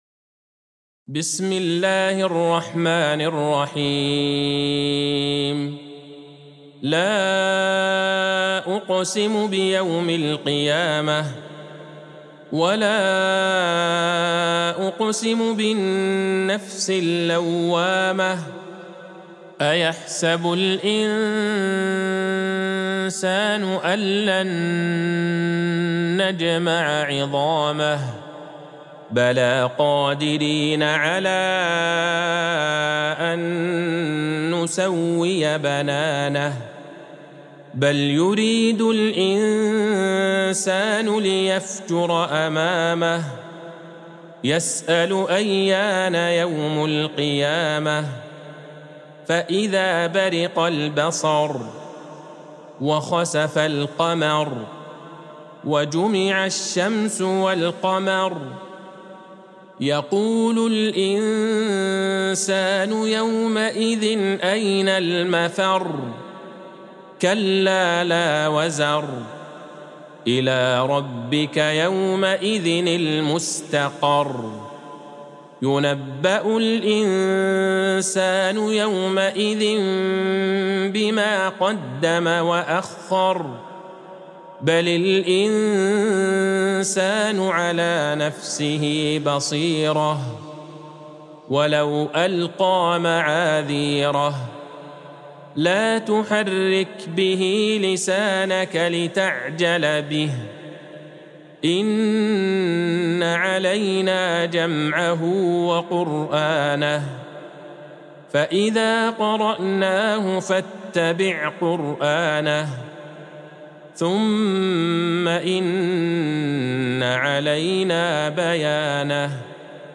سورة القيامة Surat Al-Qiyamh | مصحف المقارئ القرآنية > الختمة المرتلة